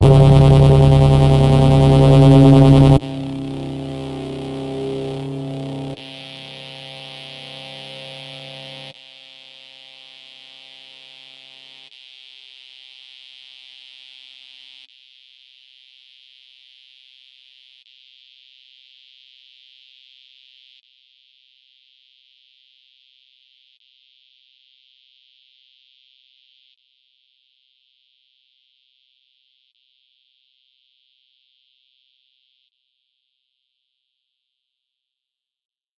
VIRAL FX 05 SHARP HARMONIC SWEEP with fading high pass " VIRAL FX 05 C3 SHARP HARMONIC SWEEP with fading high pass
描述：含有相当多的谐波内容的短小的声音，大量的方块内容，然后是逐渐消失的高通延迟。在Cubase 5中用RGC Z3TA+ VSTi制作。
标签： 效果 FX 科幻 水疗CE
声道立体声